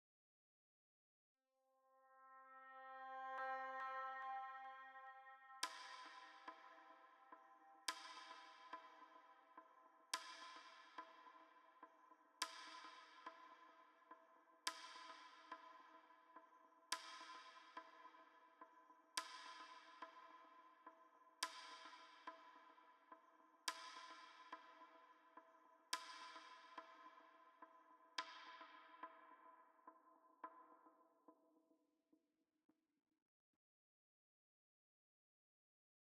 STest1_1kHz.wav